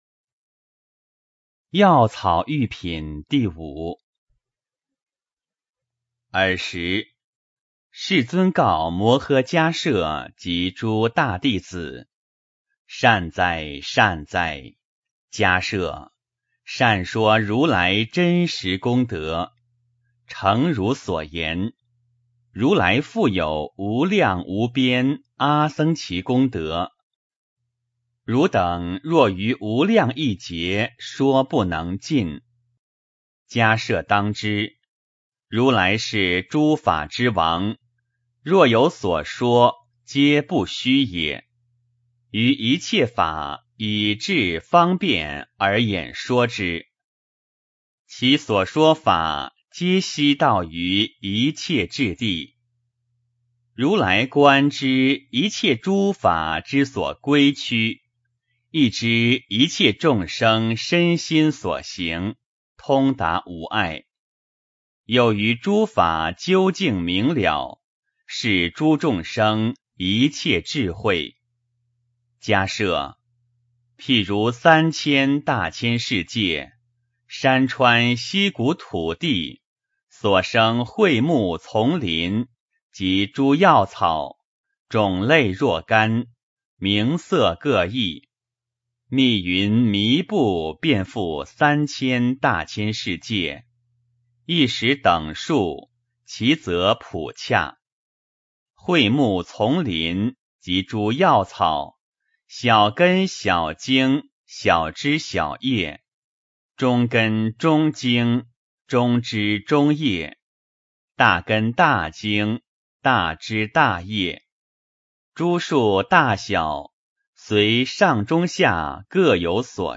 法华经-药草喻品 诵经 法华经-药草喻品--未知 点我： 标签: 佛音 诵经 佛教音乐 返回列表 上一篇： 般若波罗密多心经.唱颂 下一篇： 法华经-五百弟子受记品 相关文章 娑婆界--古乐心韵 娑婆界--古乐心韵...